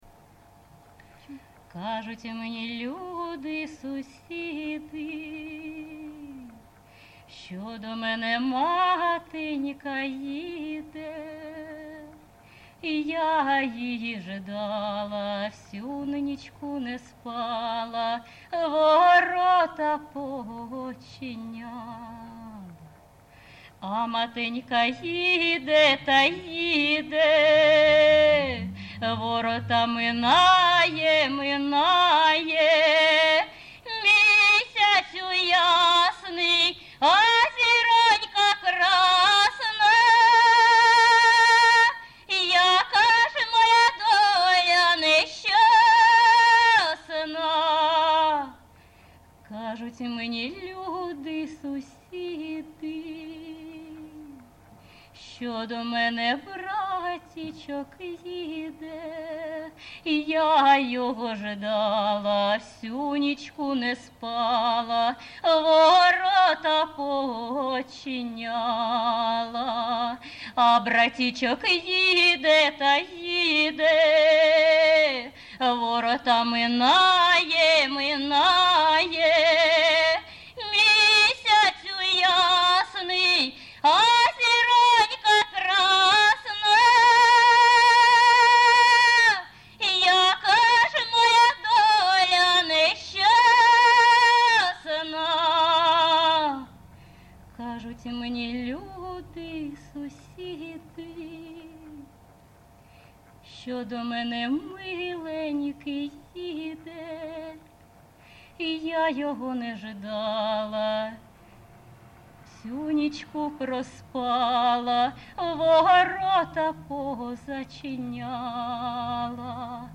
ЖанрПісні з особистого та родинного життя
Виконавиця співає не в традиційній, а в сценічній манері